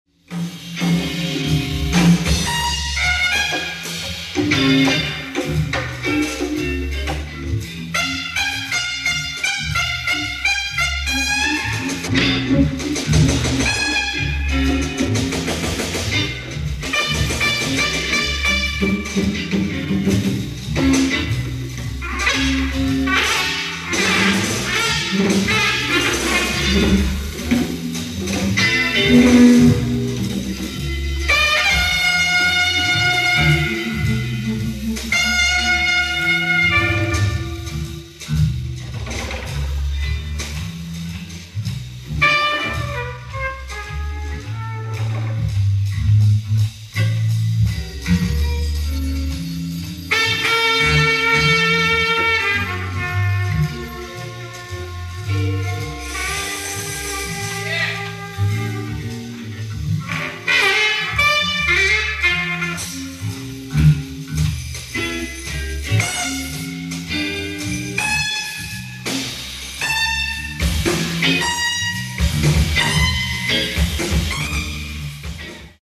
ライブ・アット・サヴォイ・シアター、ニューヨーク
※試聴用に実際より音質を落としています。